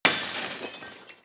sfx_window_break_big_2.wav